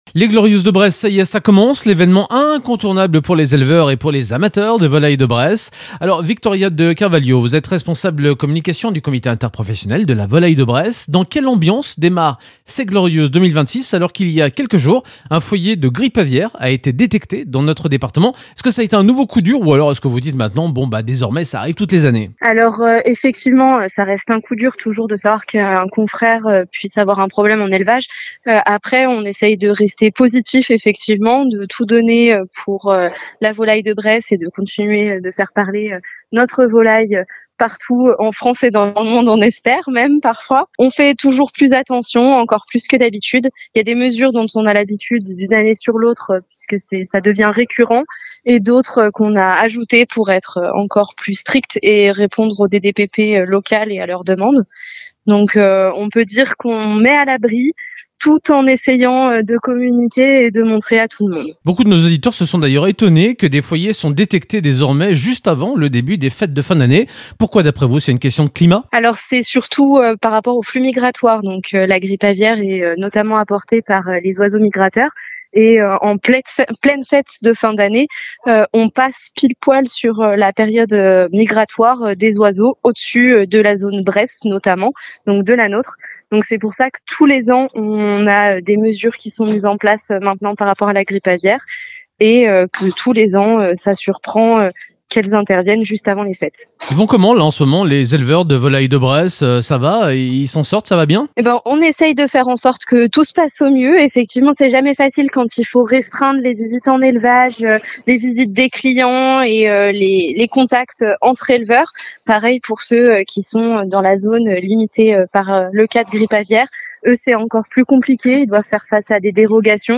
Interview de la Rédaction